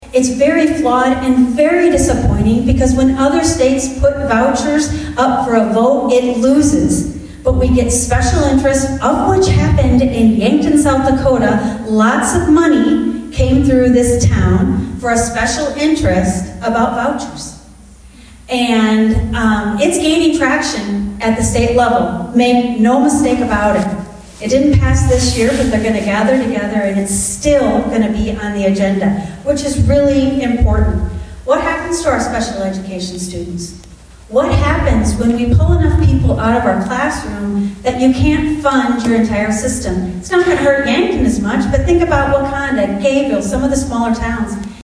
The Yankton Education Association hosted a candidate forum for the upcoming Yankton School Board election Monday night.